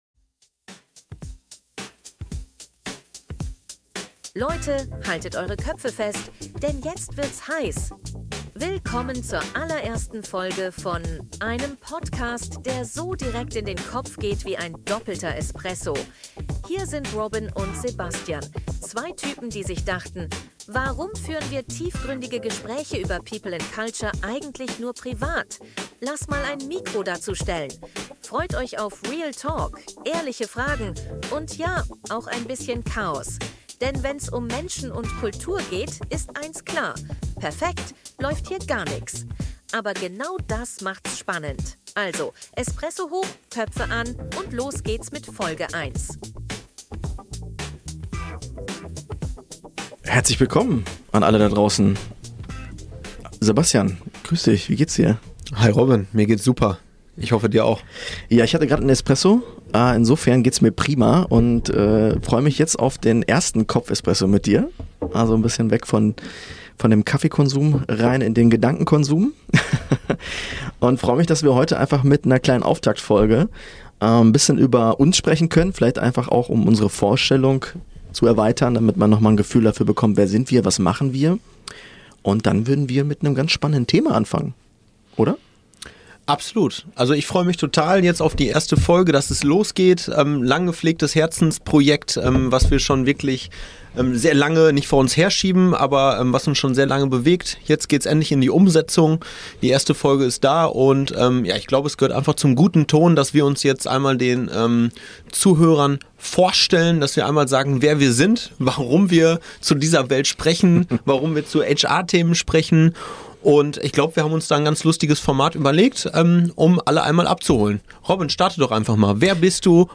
Und warum überhaupt ein weiteres Format bei dem zwei Typen miteinander quatschen?